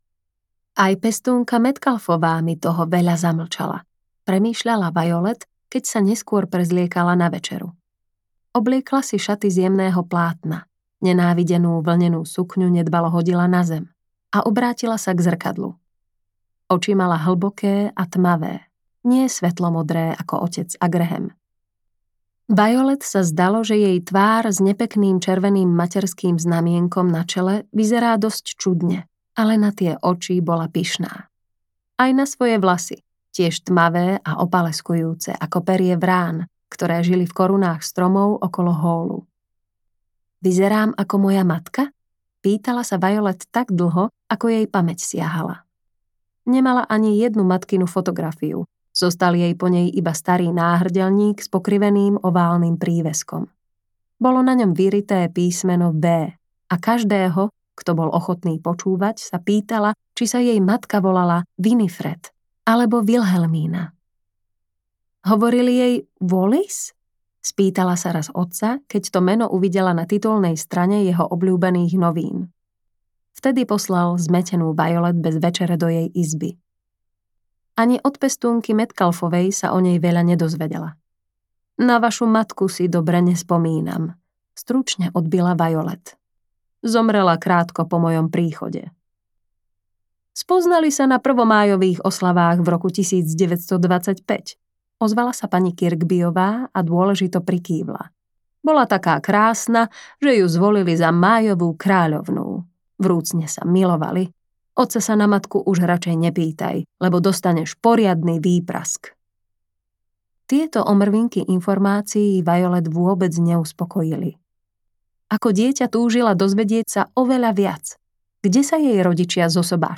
Nezlomné audiokniha
Ukázka z knihy